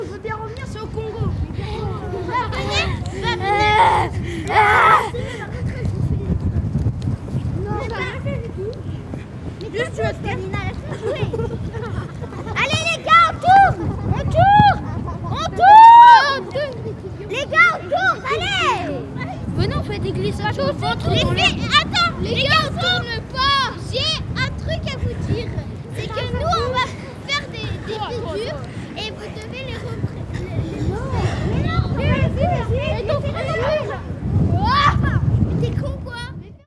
In the middle stage, we recorded additional point sound sources and used the shotgun microphone Sennheiser MKH416 to record more precise point sound sources, such as bells, chirps, kids and so on.
Kids.wav